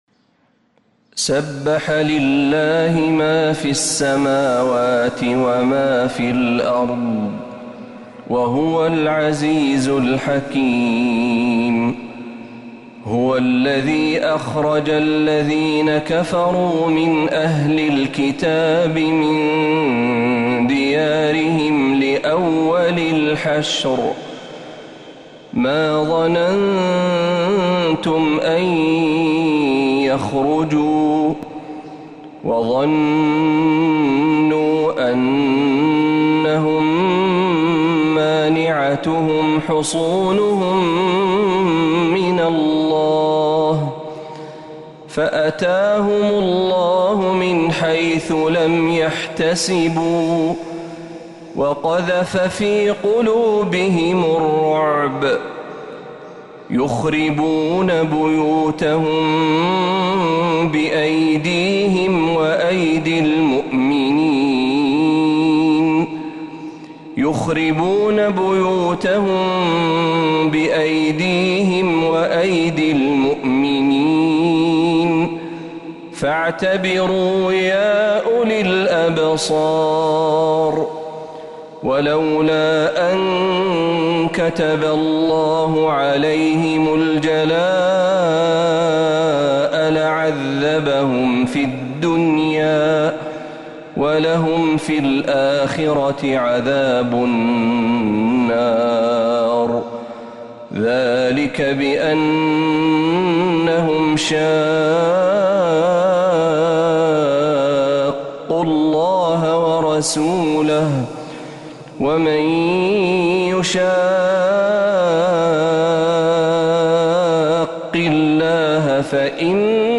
سورة الحشركاملة من الحرم النبوي > السور المكتملة للشيخ محمد برهجي من الحرم النبوي 🕌 > السور المكتملة 🕌 > المزيد - تلاوات الحرمين